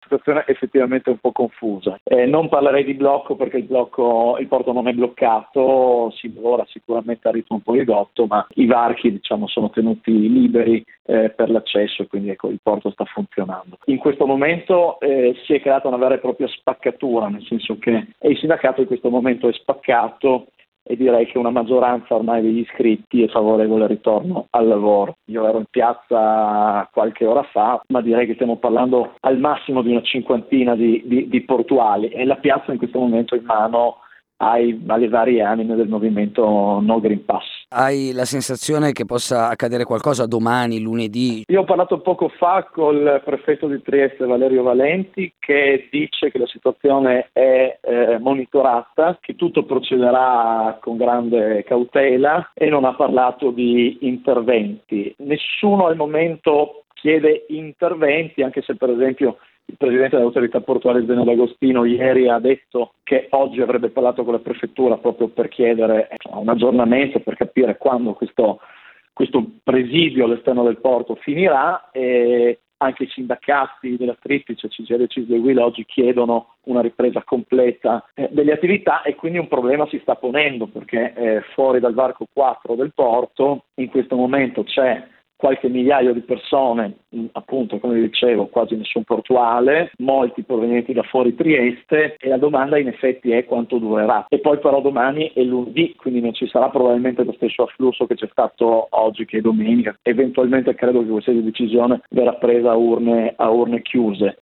Ci racconta qual’è la situazione in queste ore al porto di Trieste